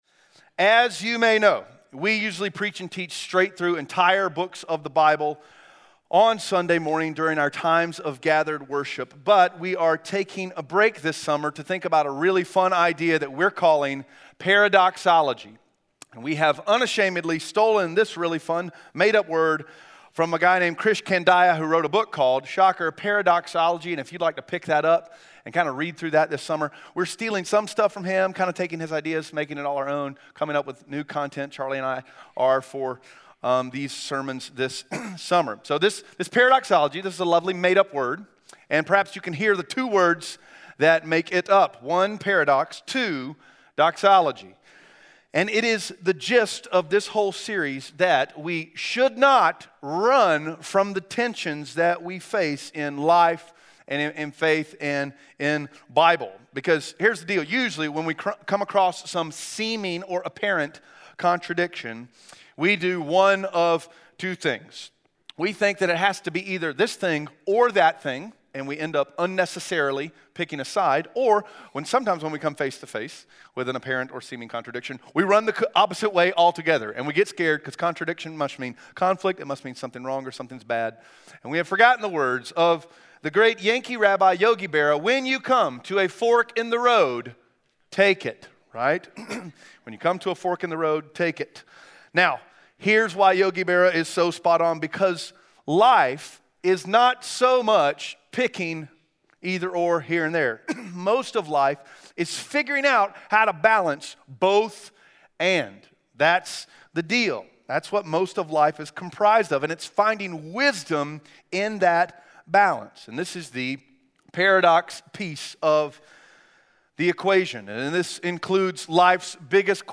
Audio Sermon Notes (PDF) Ask a Question *We are a church located in Greenville, South Carolina.